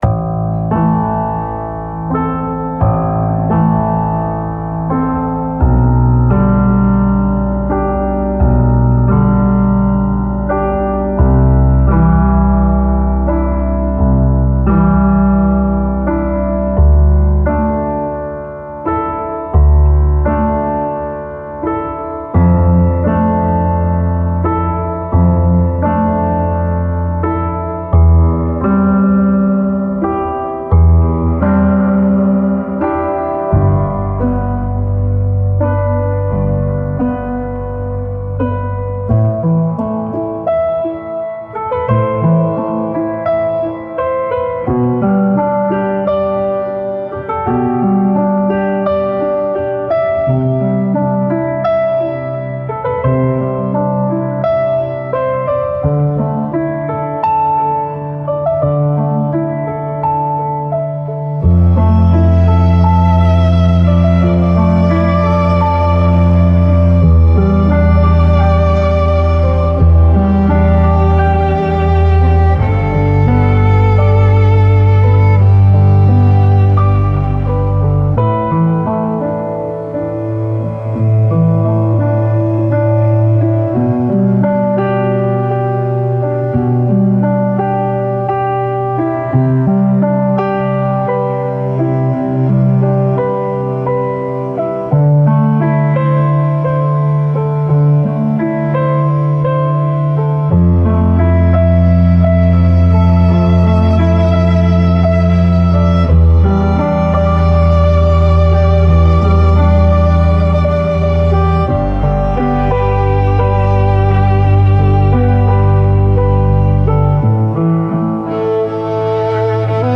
Piano solo
Genre Ambient